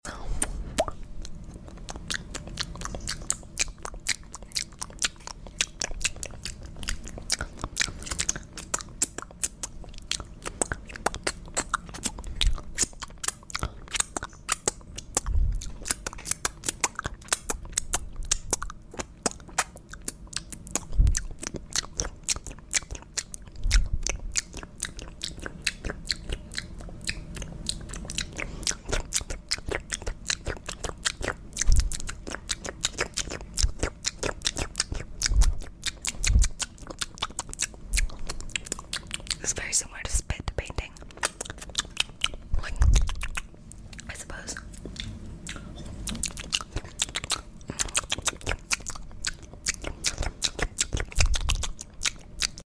Upload By Asmr